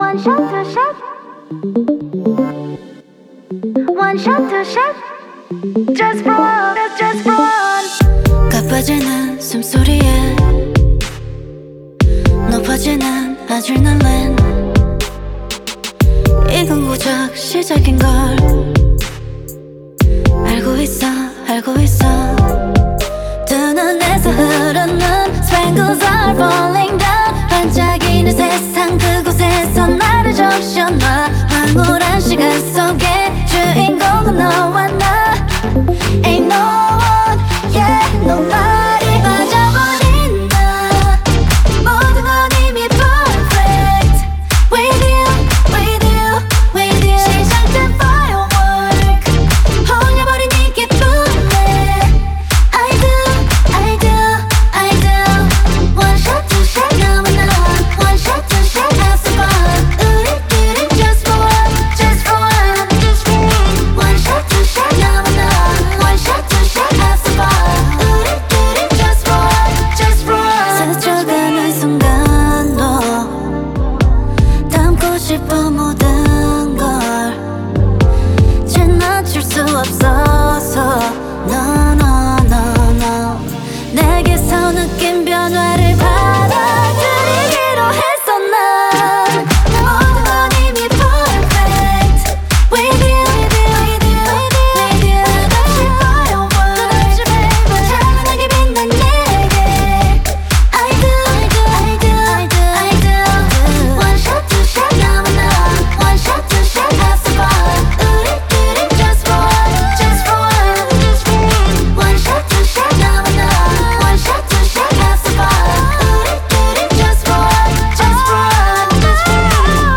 BPM120